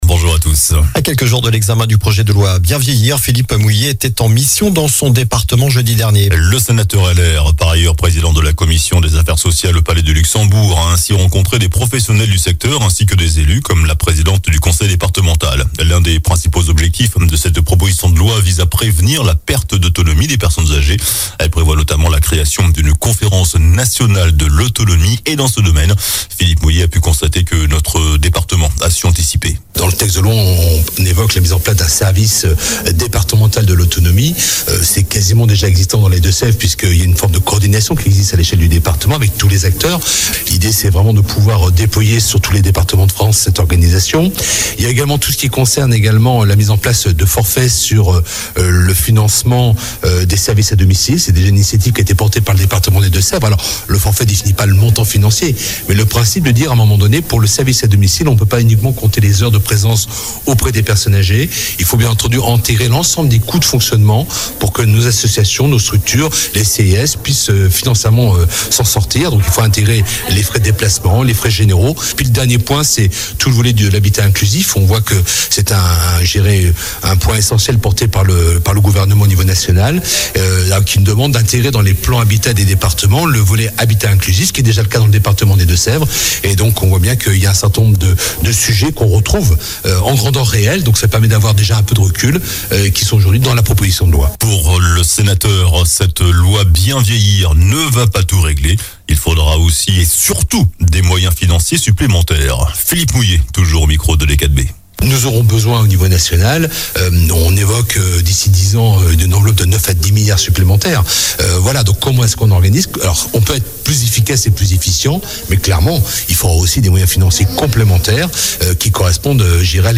JOURNAL DU SAMEDI 13 JANVIER